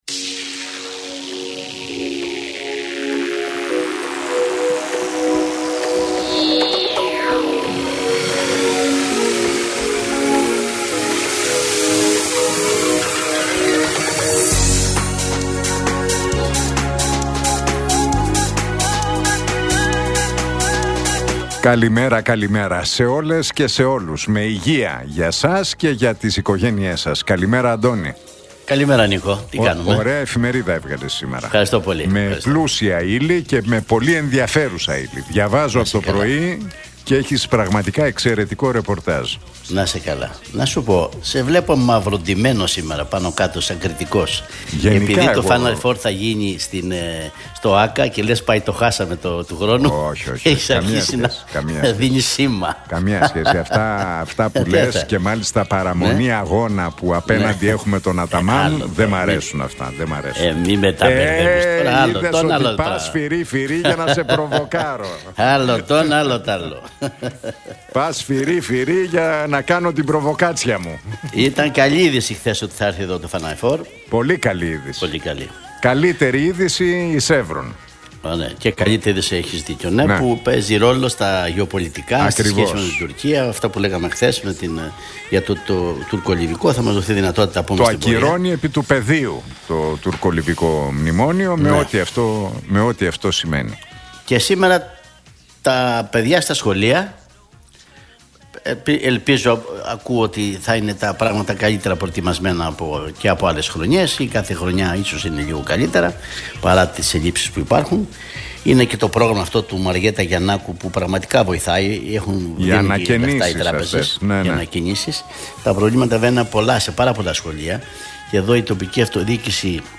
Ακούστε την εκπομπή του Νίκου Χατζηνικολάου στον ραδιοφωνικό σταθμό RealFm 97,8, την Πέμπτη 11 Σεπτεμβρίου 2025.